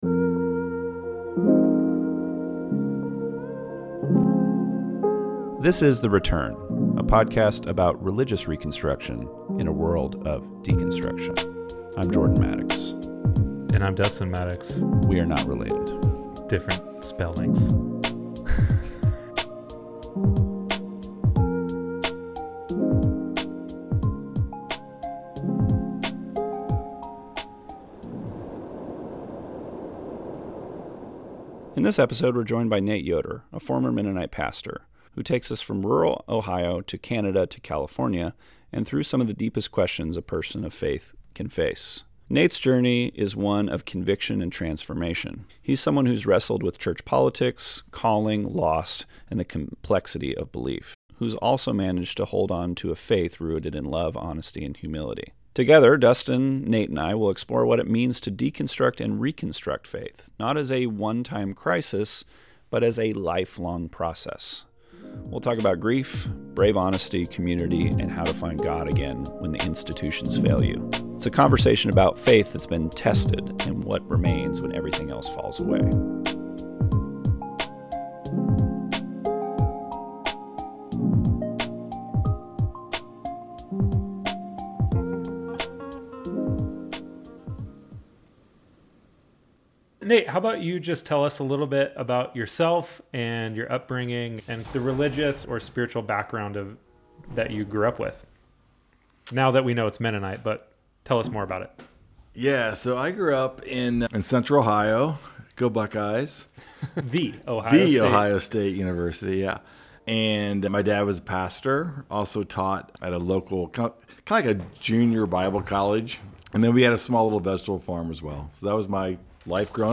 This conversation explores deconstruction and reconstruction not as a crisis, but as an ongoing process of growth. Together, the three discuss brave honesty in spiritual life, how to stay grounded when institutions disappoint, and why the core of Christian faith still comes back to one thing: love God and love others.